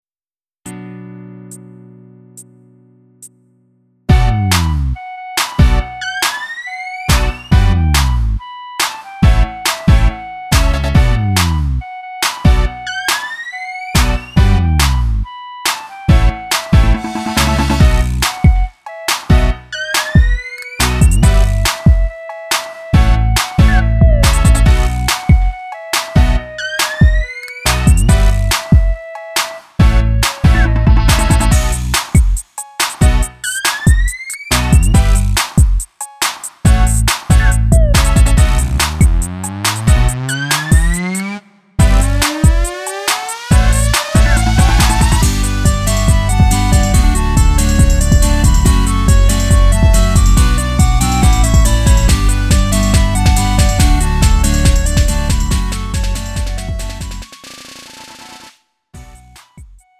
음정 원키 2:53
장르 구분 Lite MR